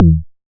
edm-kick-34.wav